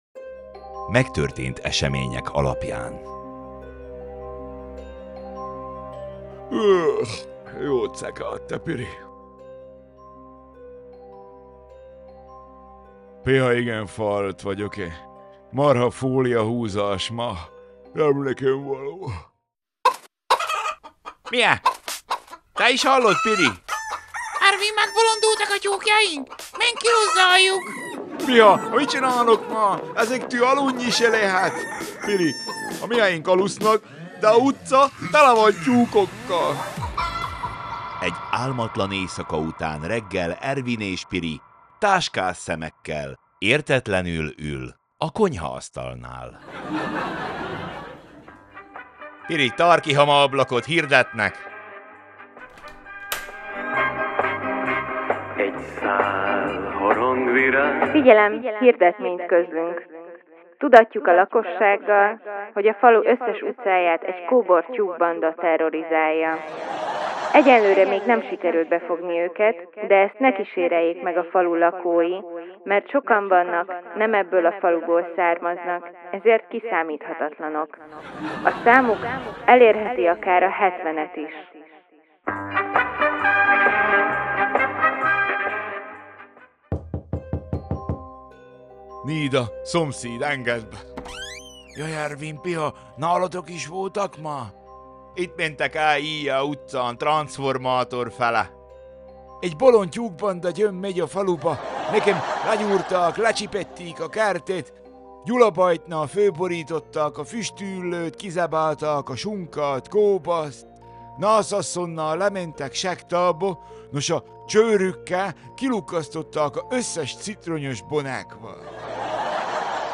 Szaval a jó öreg csallóközi: Támonnak a tyúkok